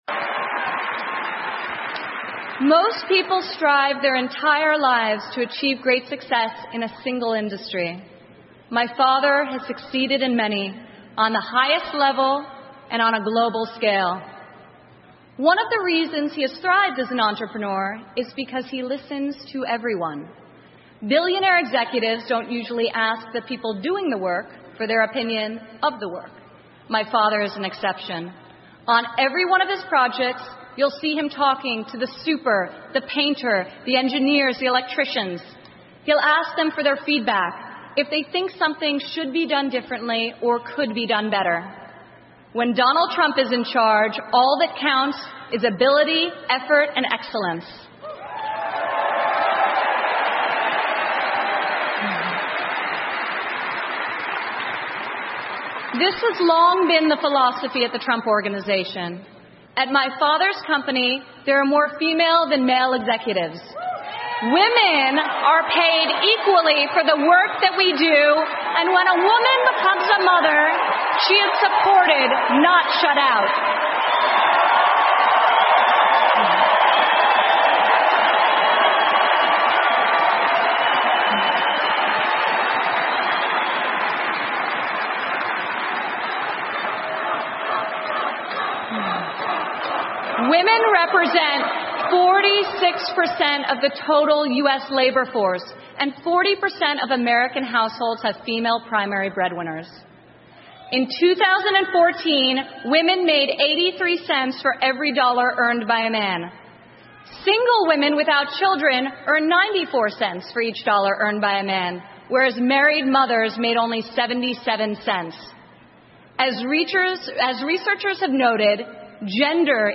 美国总统大选演讲 听力文件下载—在线英语听力室